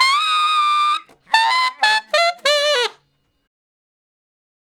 066 Ten Sax Straight (D) 32.wav